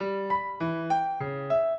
piano
minuet12-6.wav